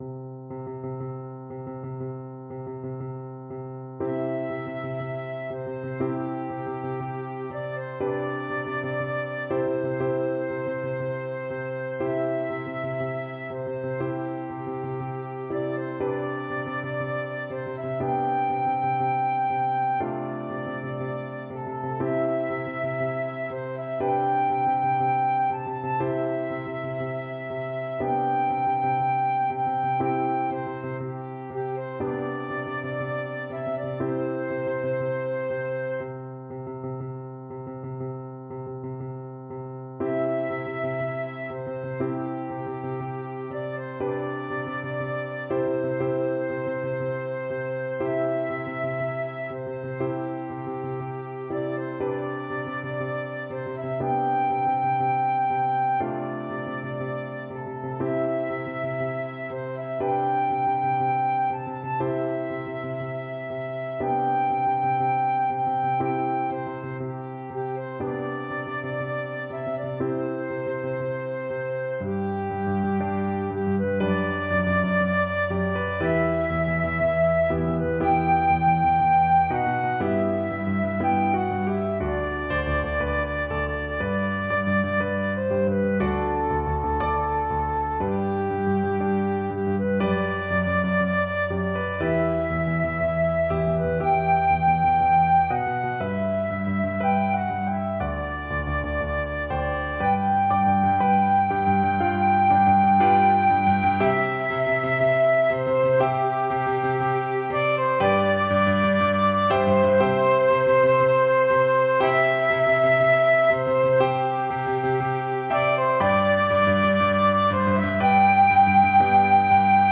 FluteFlute
4/4 (View more 4/4 Music)
Nobilmente = c. 60
Arrangement for Flute and Piano
C major (Sounding Pitch) (View more C major Music for Flute )
Traditional (View more Traditional Flute Music)